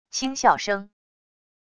轻笑声wav音频